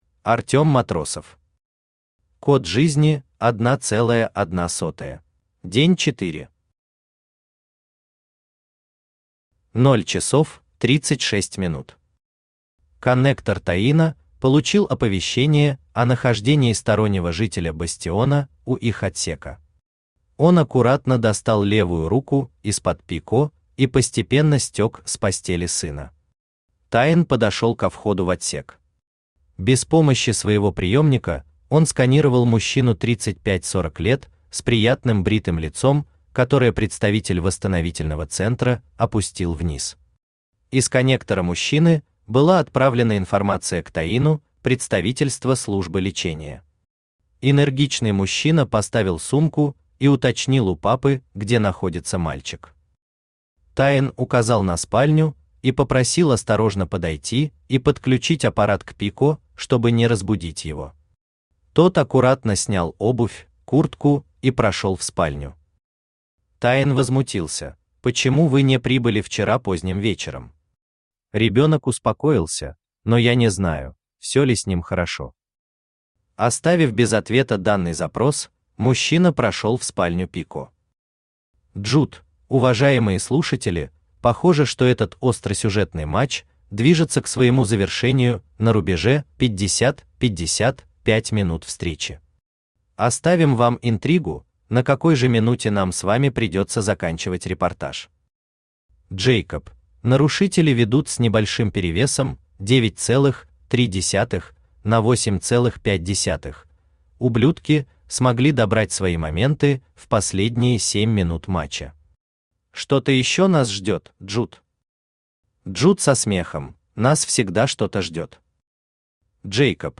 Аудиокнига Код Жизни 1.01 | Библиотека аудиокниг
Читает аудиокнигу Авточтец ЛитРес.